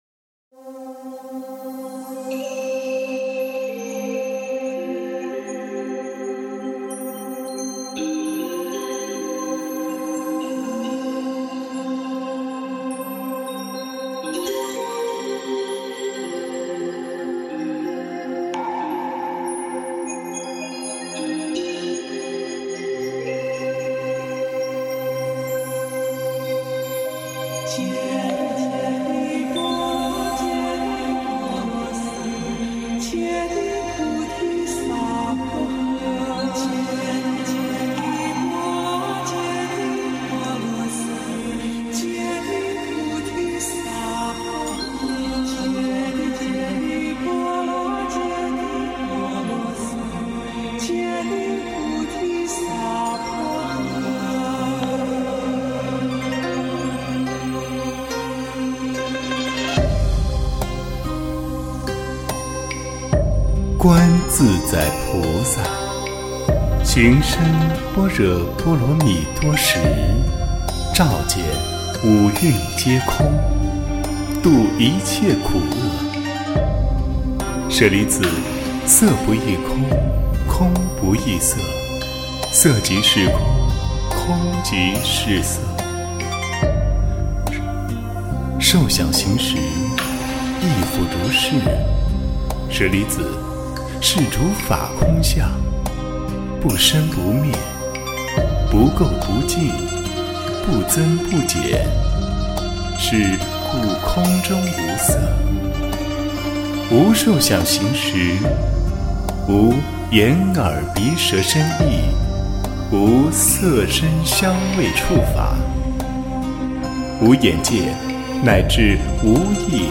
心经（念诵）
诵经